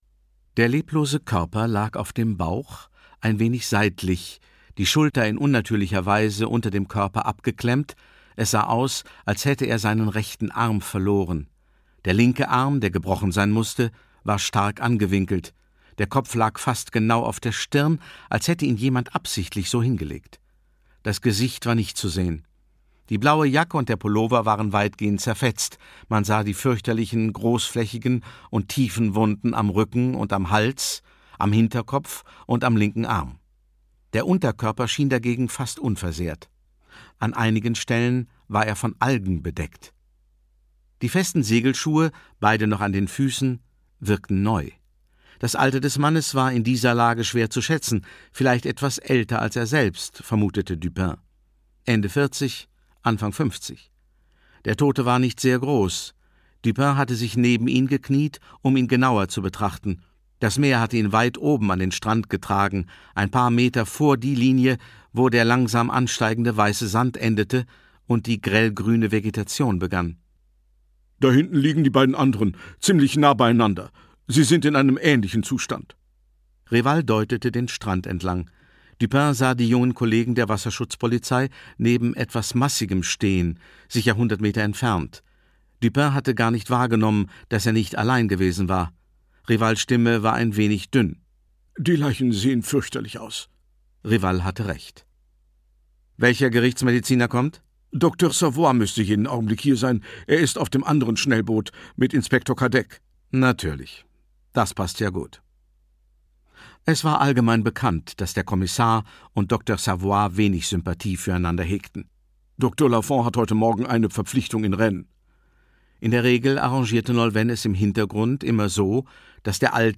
Bretonische Brandung Kommissar Dupins zweiter Fall Jean-Luc Bannalec (Autor) Gerd Wameling (Sprecher) Audio Disc 2020 | 1.